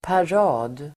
Uttal: [par'a:d]